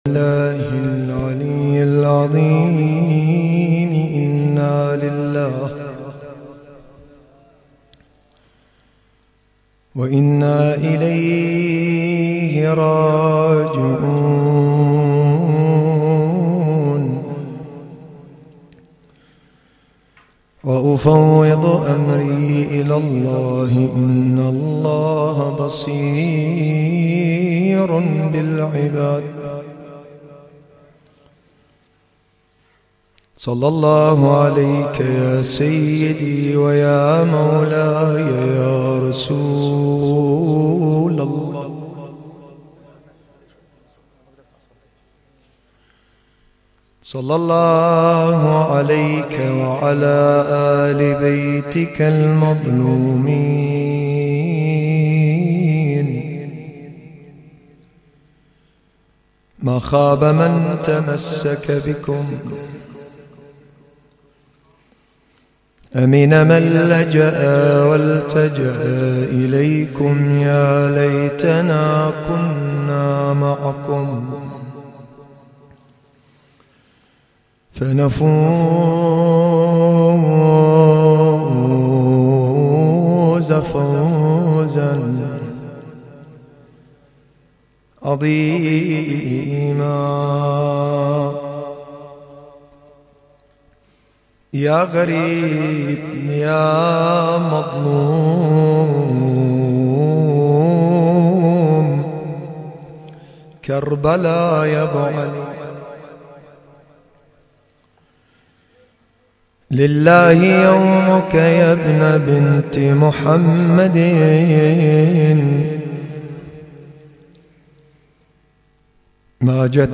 تغطية صوتية: إعادة العشرة الحسينية ليلة 9 محرم 1440هـ